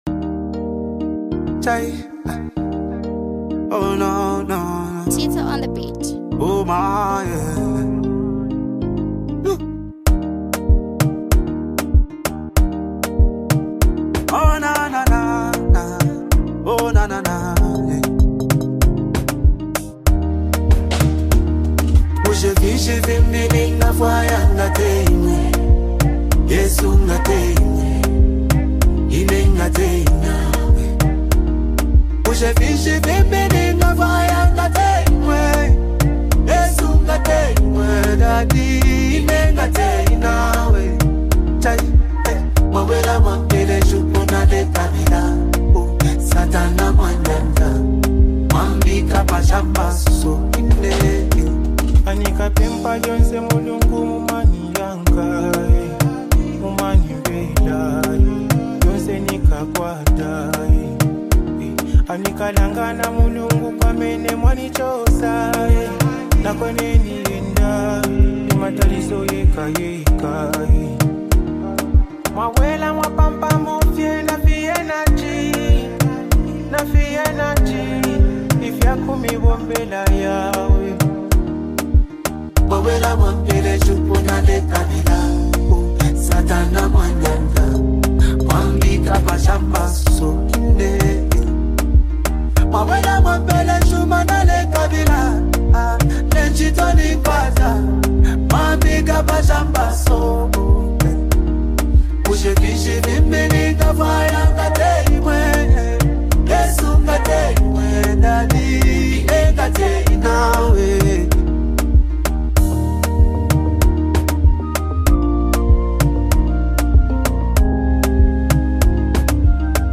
LATEST ZAMBIAN WORSHIP SONG